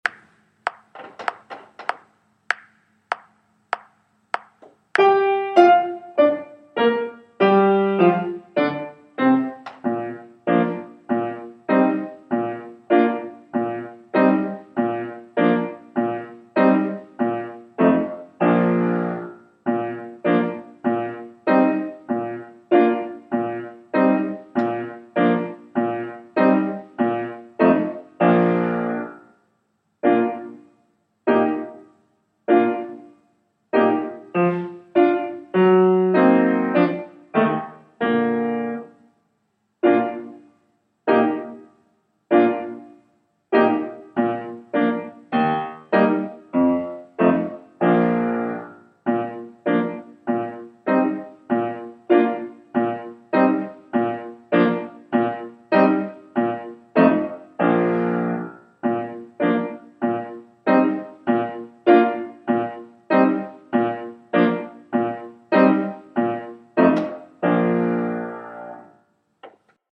Shepherds Hey, piano part Bb